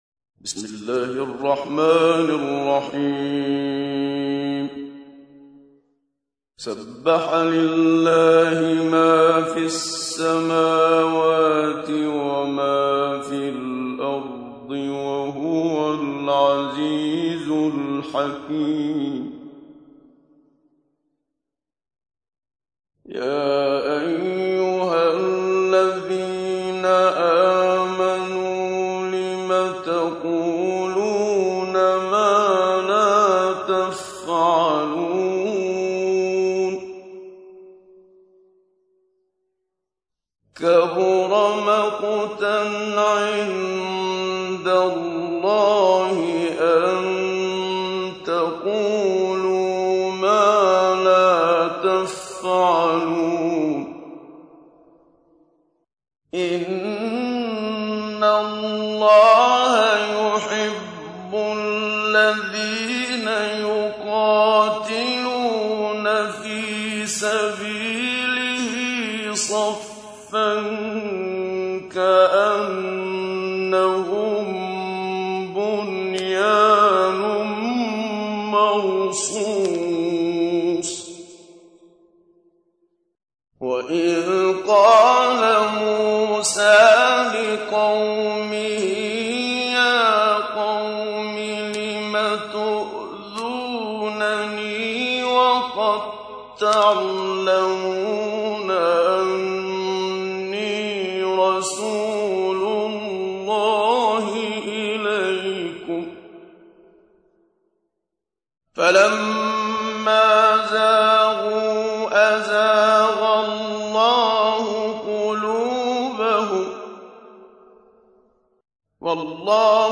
تحميل : 61. سورة الصف / القارئ محمد صديق المنشاوي / القرآن الكريم / موقع يا حسين